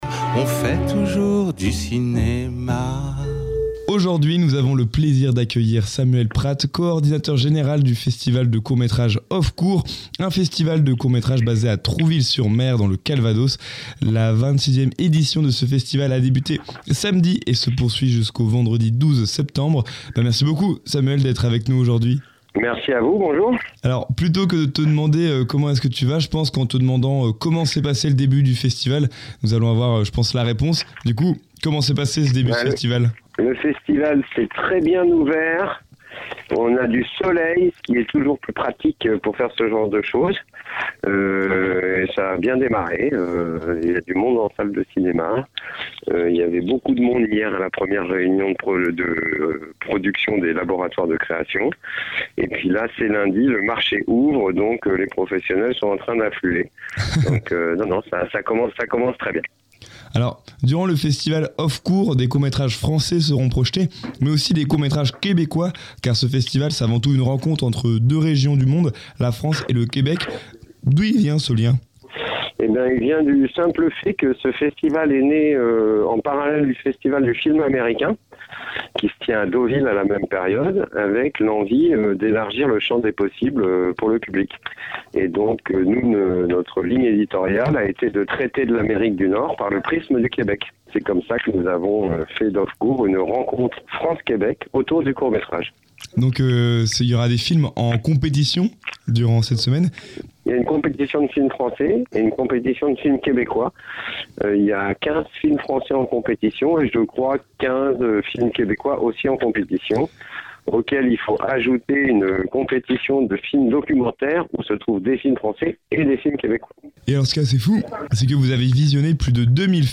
Dans cette ITW, il est revenu sur les nombreux films en compétition et hors compétition de cette 26ᵉ édition, ainsi que sur les autres animations du festival Off-Courts.